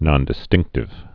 (nŏndĭ-stĭngktĭv)